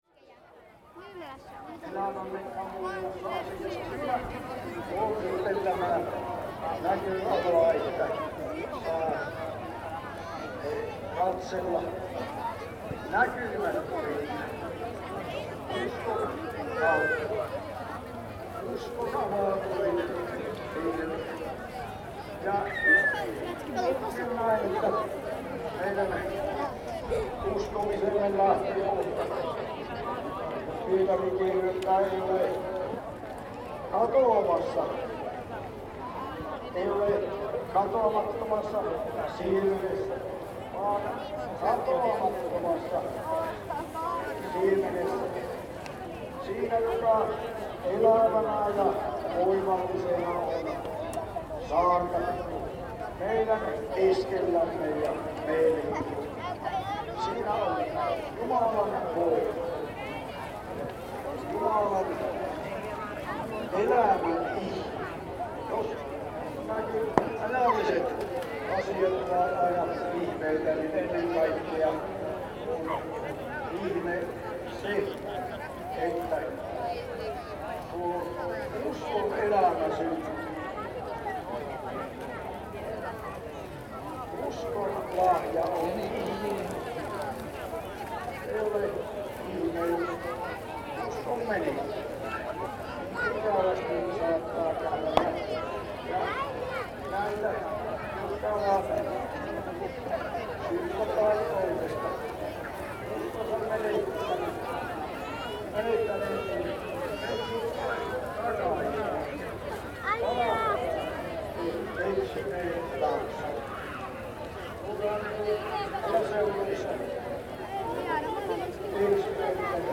Summer services is the biggest spiritual meeting in Finland and one of the biggest summer festivals gathering somewhat 80000 people every year.
• Soundscape
• gathering